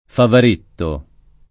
[ favar % tto ]